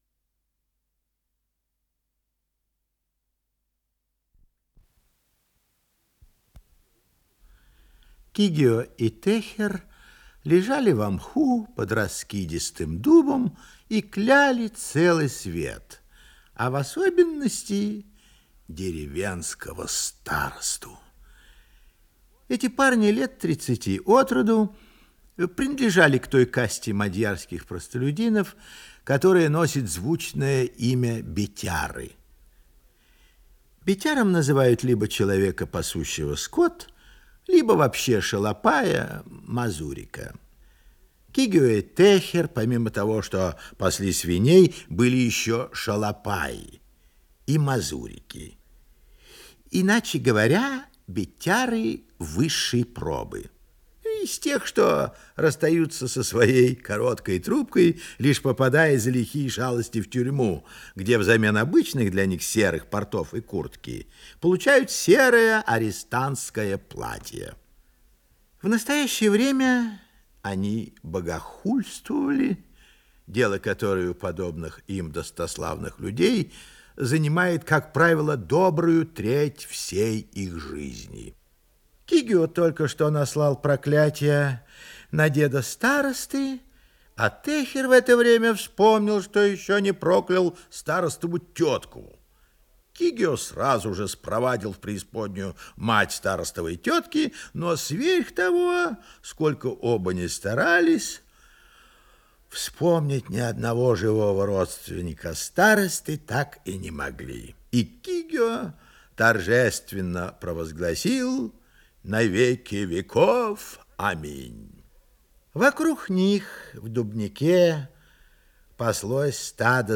Исполнитель: Анатолий Папанов - чтение
Рассказ